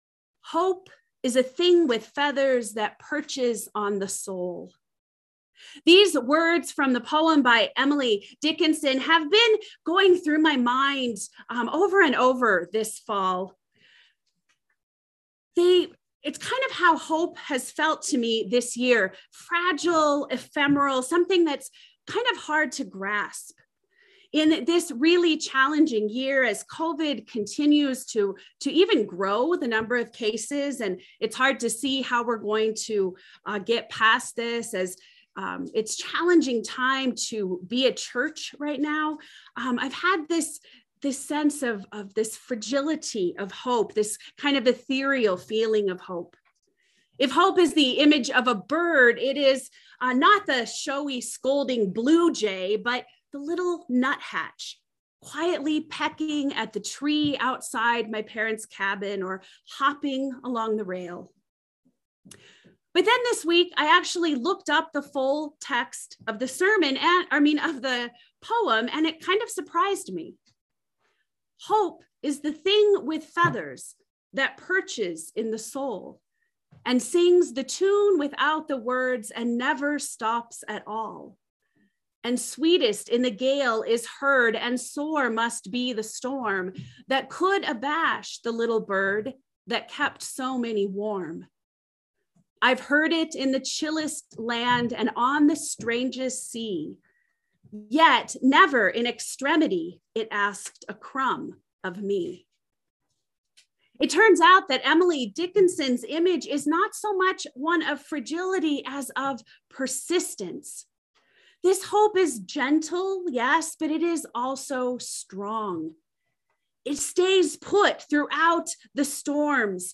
Sermon test - All Saints Lutheran Church, ELCA